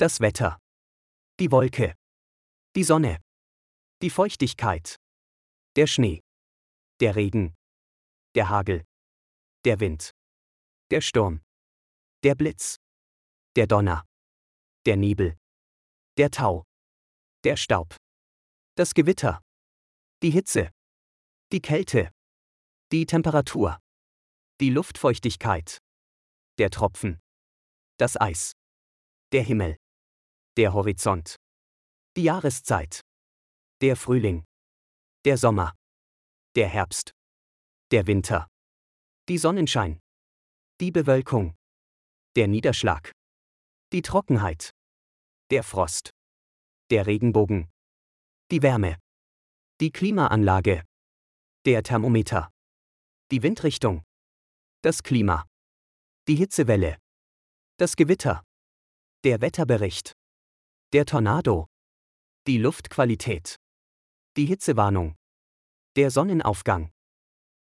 سنضع لكم لفظ كل كلمة مكتوبة بالعربي وايضاً صوتياً لأن كلمات المانية مكتوبة بالعربي تساعد المبتدئين في تعلم اللغة الألمانية بشكل أفضل وأسرع.